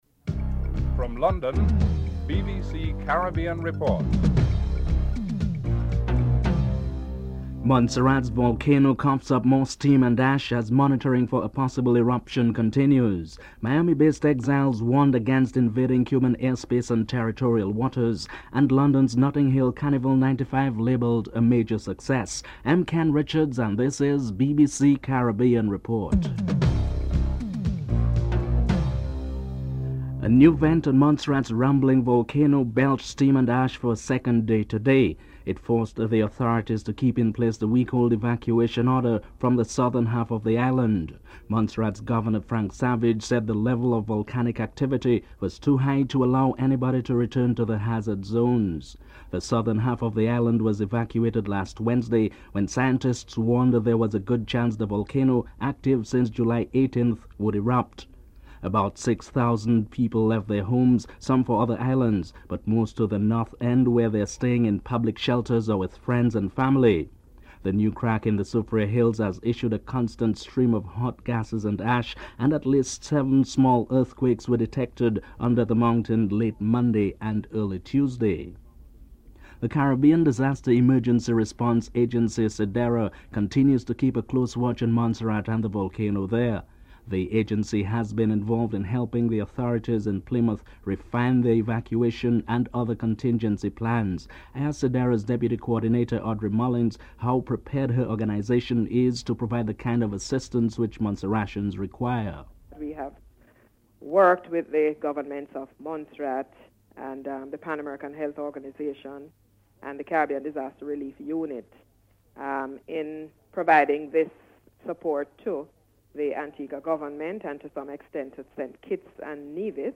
Headlines
9. Recap of top stories (14:46-15:05)